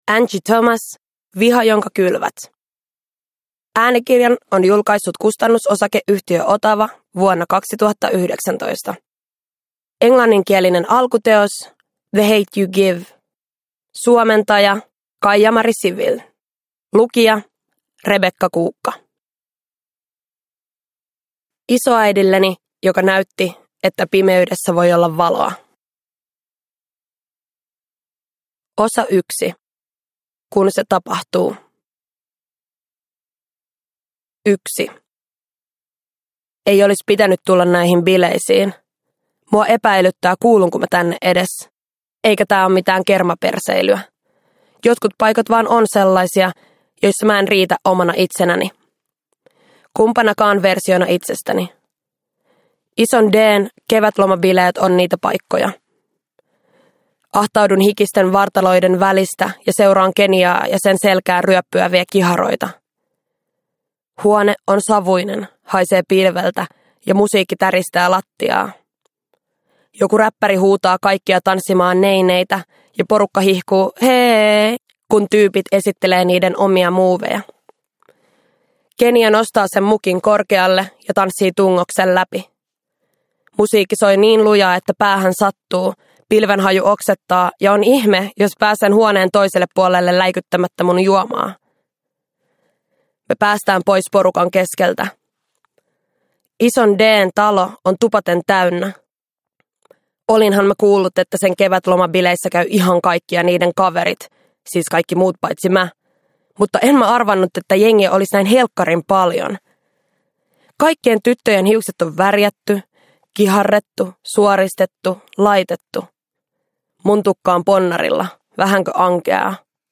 Produkttyp: Digitala böcker